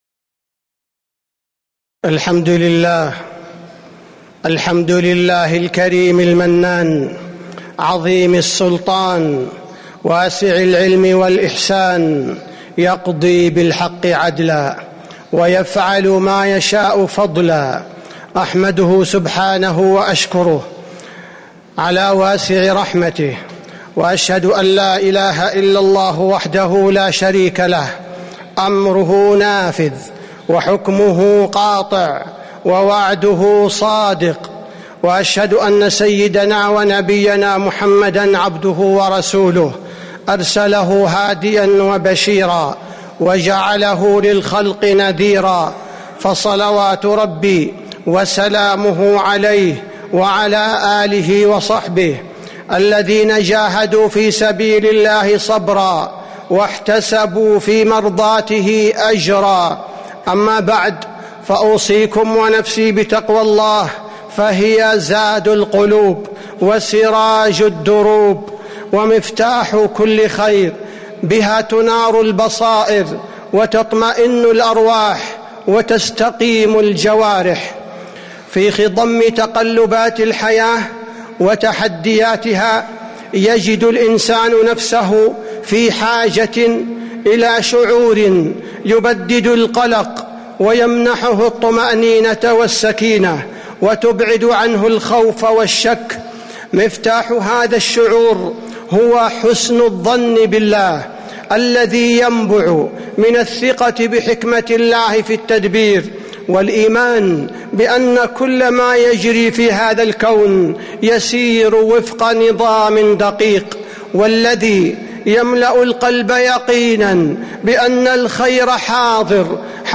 تاريخ النشر ٢٠ جمادى الأولى ١٤٤٦ هـ المكان: المسجد النبوي الشيخ: فضيلة الشيخ عبدالباري الثبيتي فضيلة الشيخ عبدالباري الثبيتي حسن الظن بالله تعالى